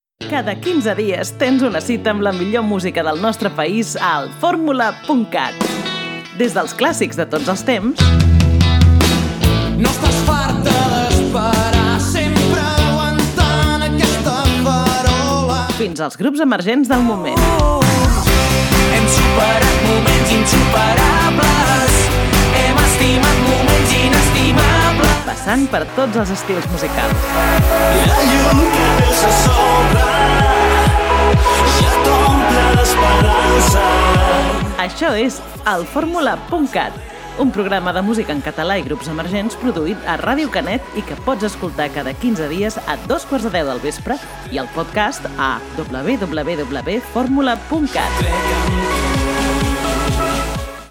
Indicatiu del programa